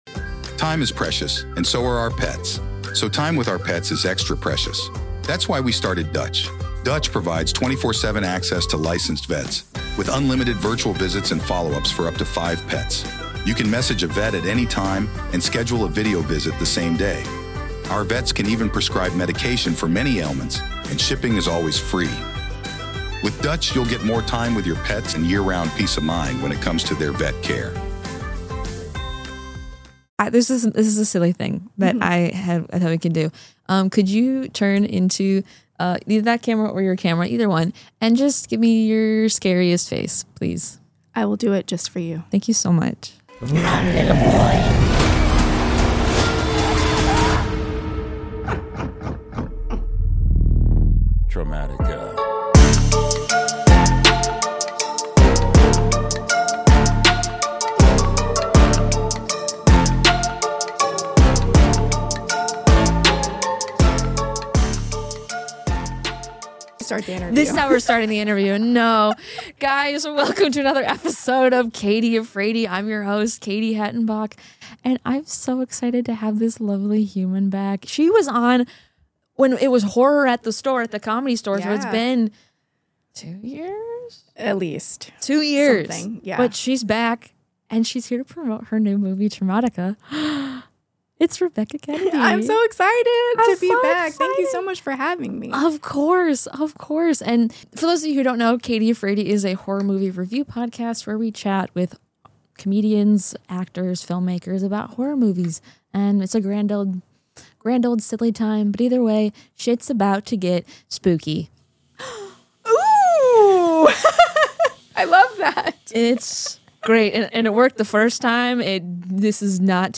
The Cave Podcast Studio